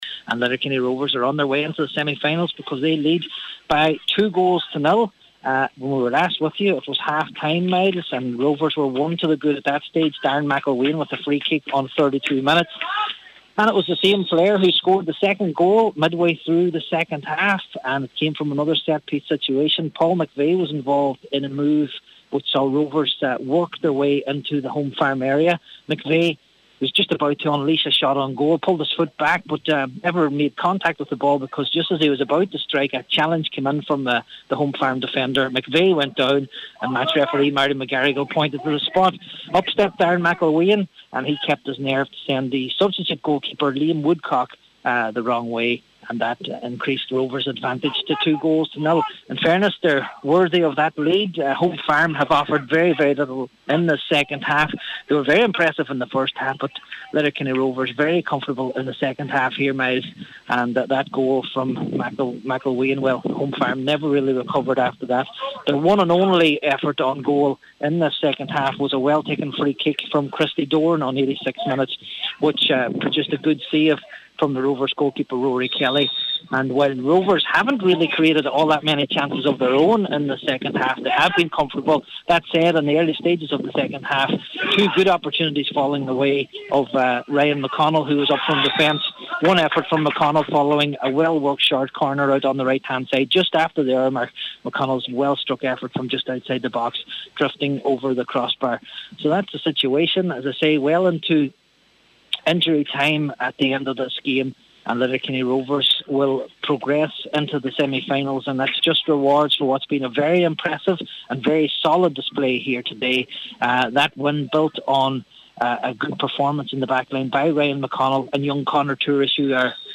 full-time report for Highland Radio